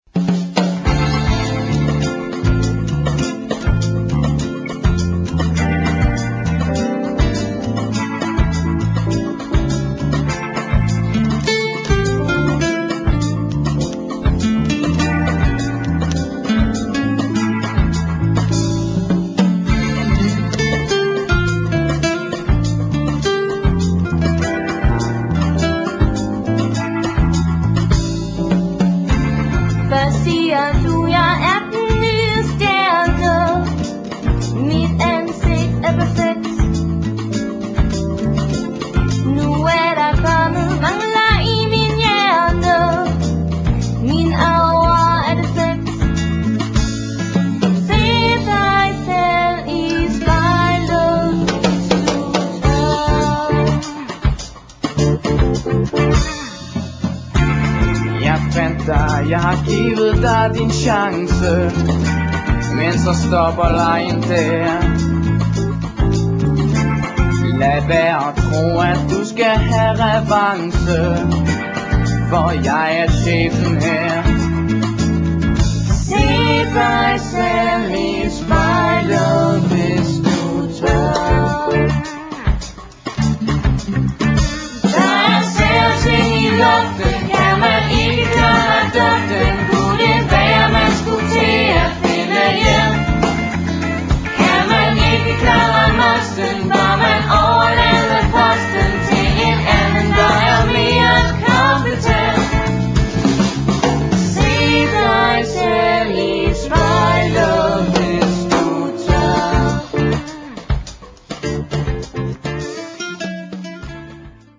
Der er både pop, rock og rumba.